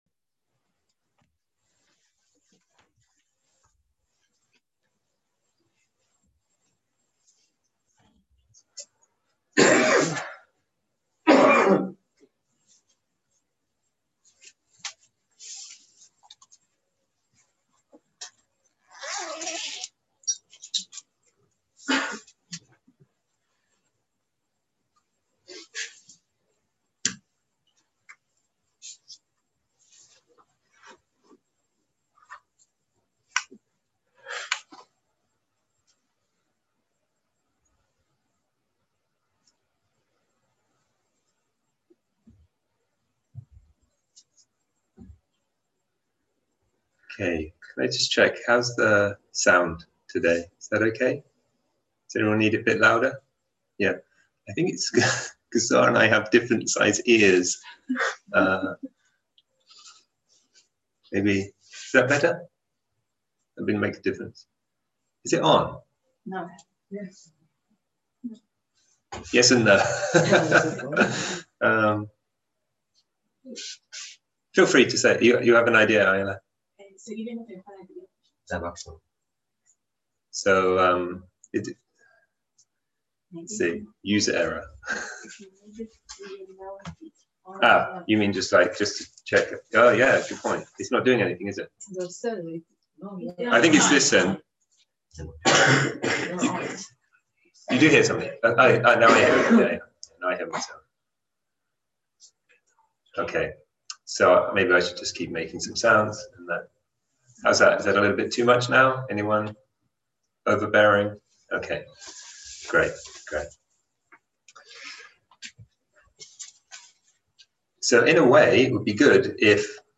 שיחת הנחיות למדיטציה שפת ההקלטה